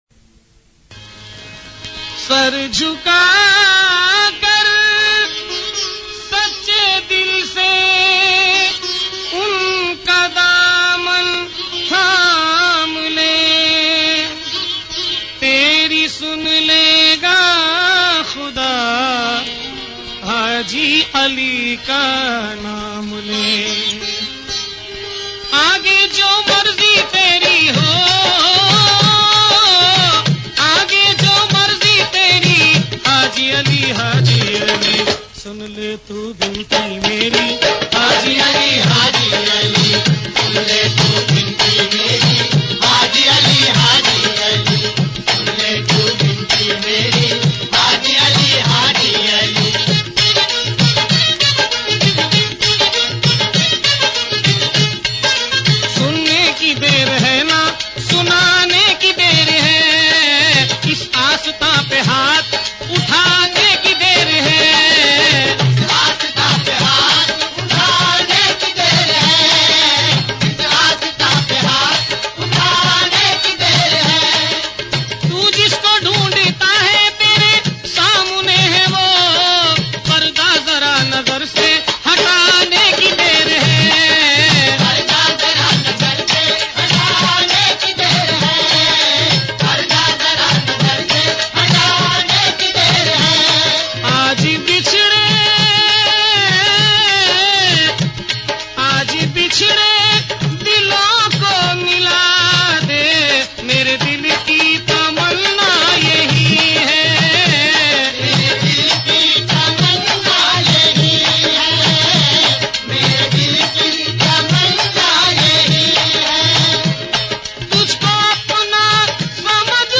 Islamic Qawwalies And Naats > Dargahon Ki Qawwaliyan